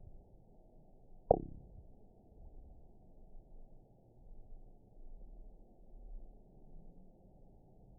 event 922797 date 04/06/25 time 06:19:03 GMT (2 months, 1 week ago) score 7.01 location TSS-AB04 detected by nrw target species NRW annotations +NRW Spectrogram: Frequency (kHz) vs. Time (s) audio not available .wav